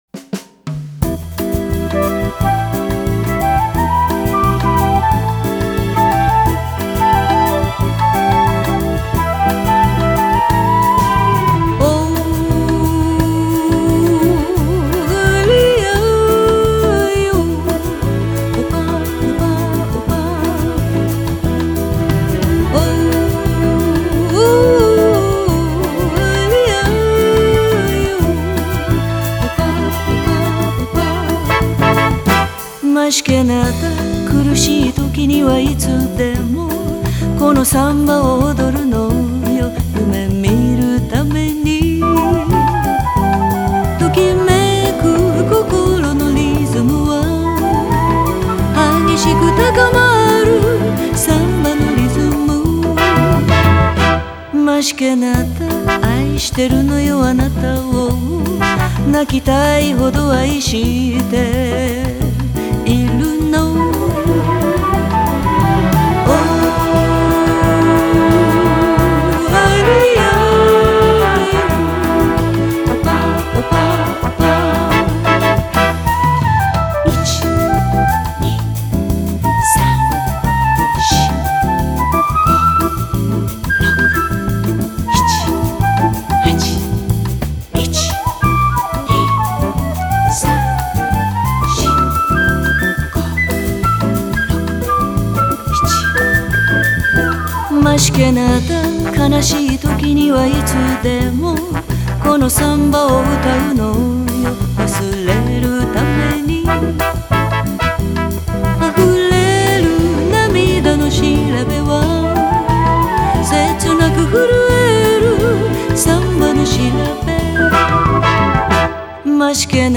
Genre: Jazz,Latin